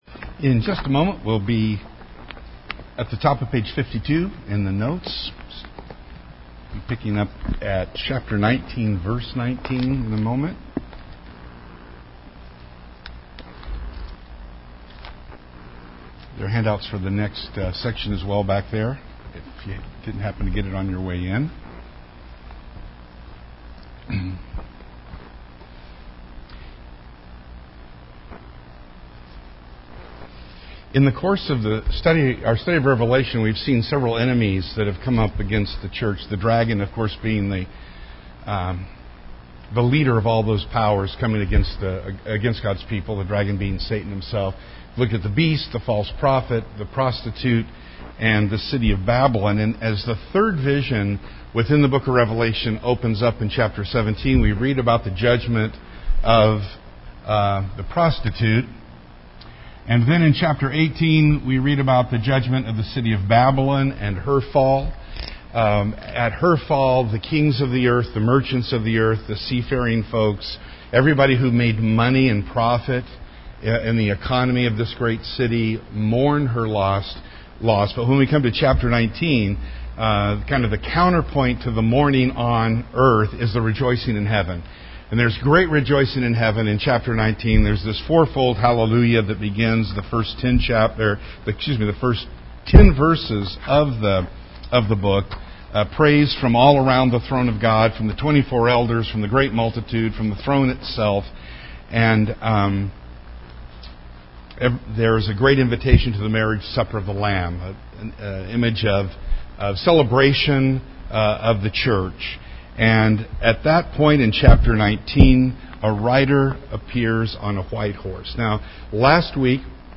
This is the twenty-eighth part of our Wednesday night class on Revelation.